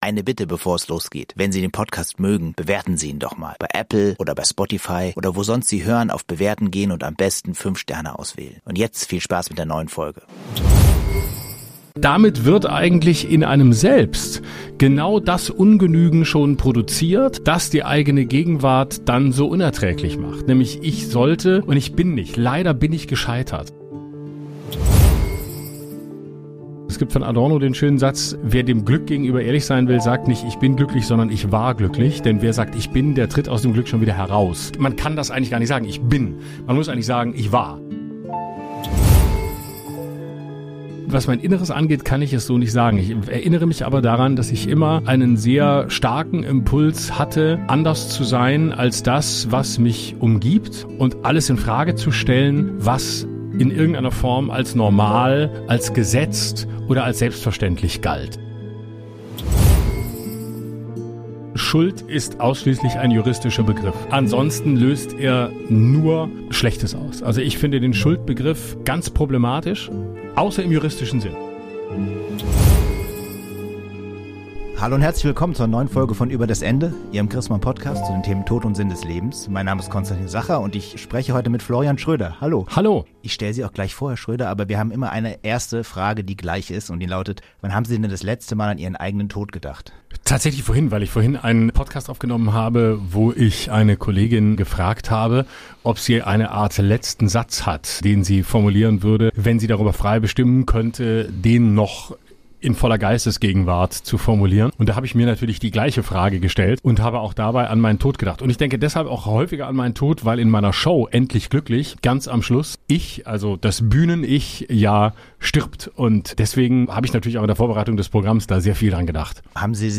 Ein Gespräch über Freiheit, Verantwortung und die Frage, was ein gutes Leben ausmacht.